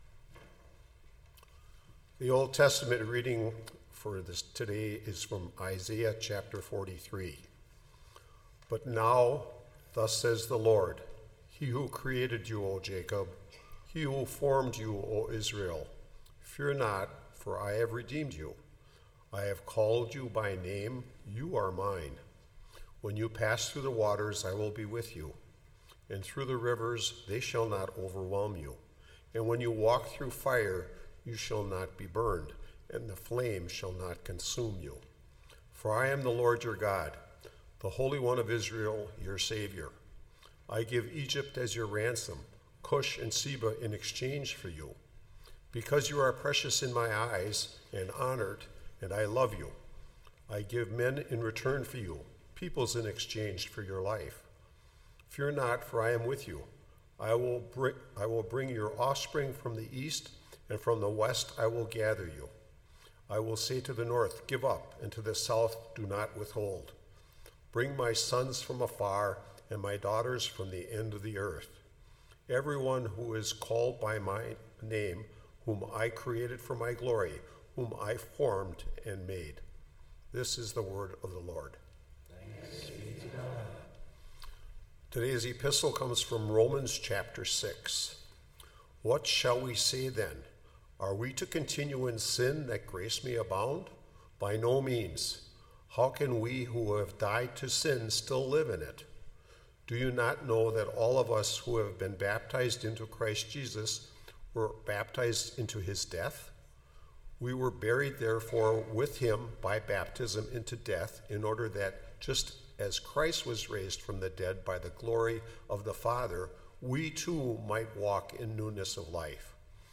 That’s what this sermon explores.